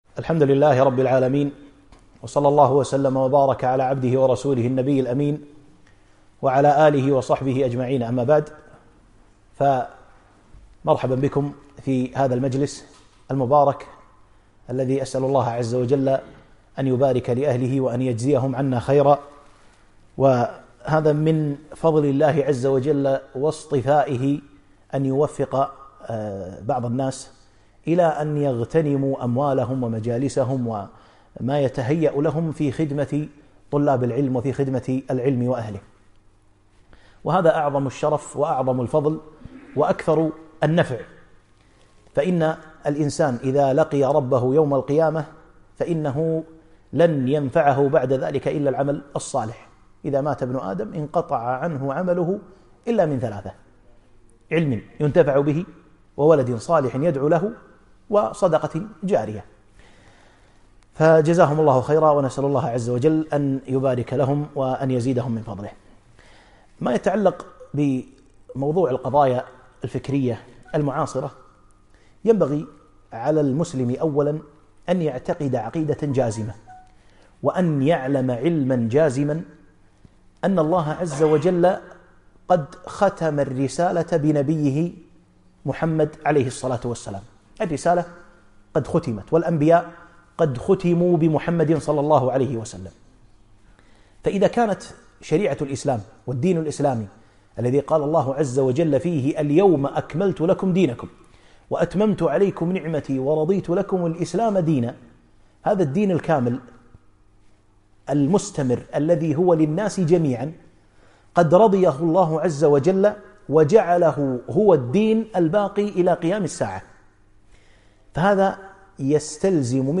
محاضرة - قضايا فكرية معاصرة